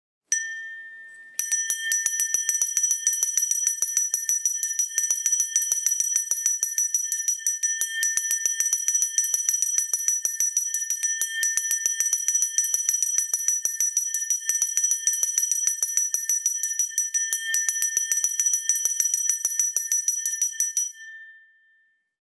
Звуки школьного колокольчика
Школьный звонок - Вариант 3